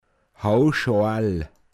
Wortlisten - Pinzgauer Mundart Lexikon
Haarscheitel Håoschoal, m.